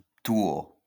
Touho (French pronunciation: [tu.o]